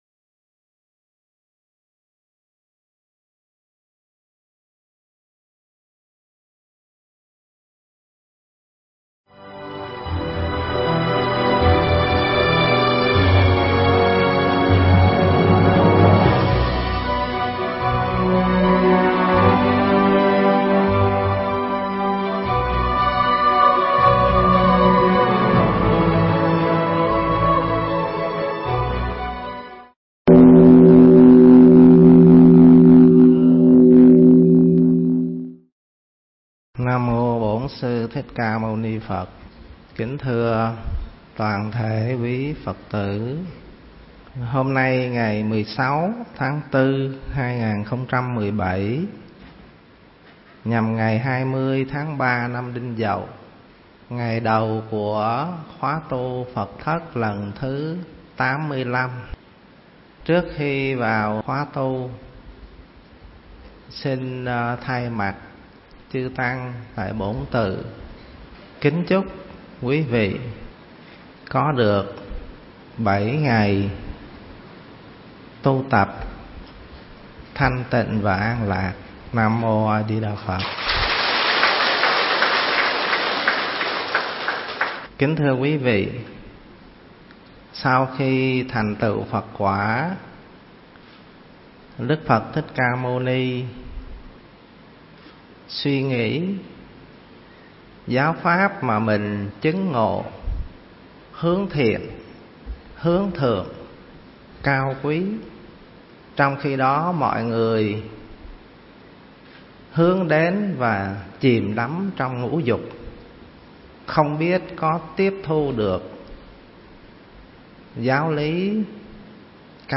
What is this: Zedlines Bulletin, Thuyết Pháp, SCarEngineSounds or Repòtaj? Thuyết Pháp